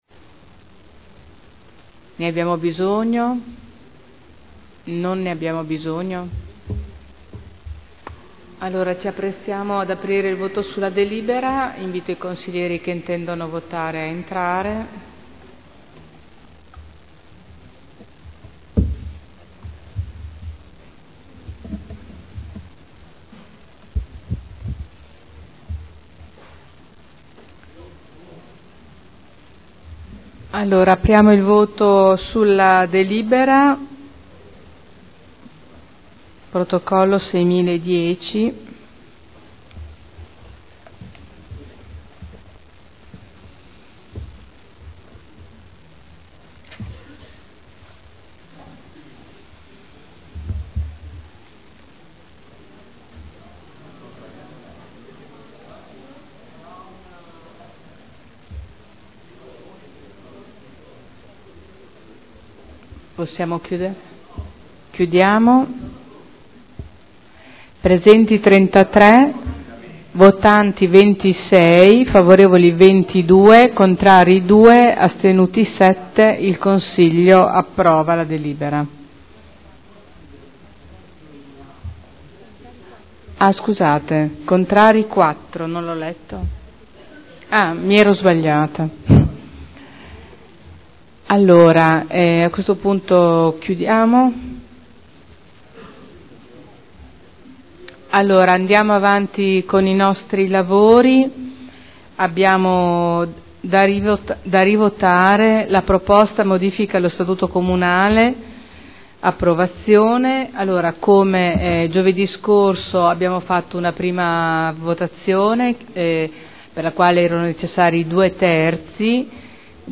Seduta del 24 febbraio. Proposta di deliberazione: Aggiornamento della classificazione acustica del territorio comunale – Adozione.